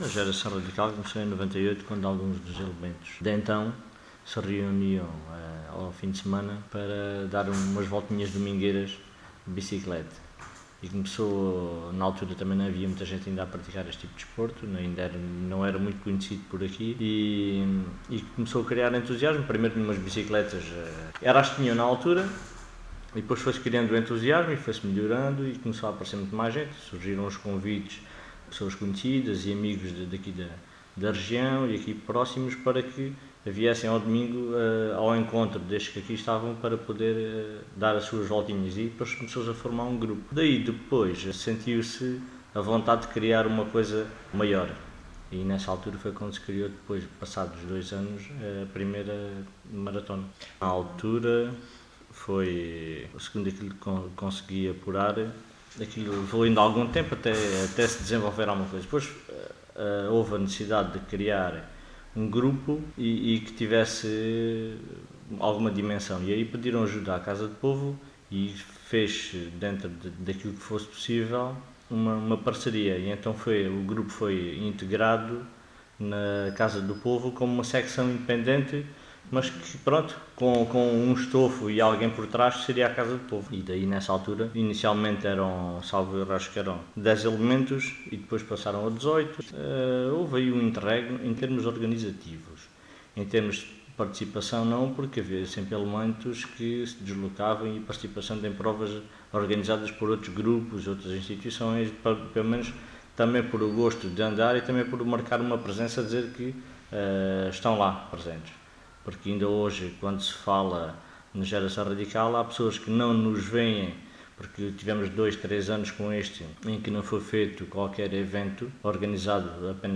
em entrevista